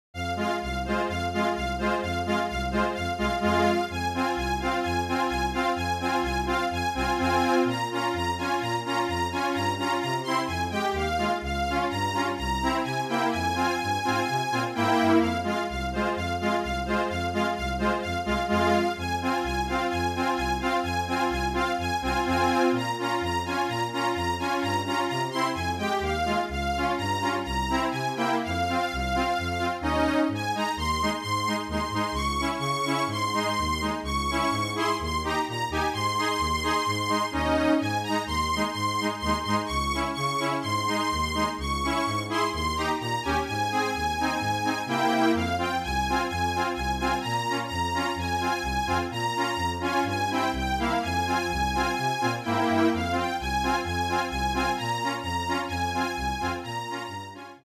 Flute, Violin and Cello (or Two Violins and Cello)
MIDI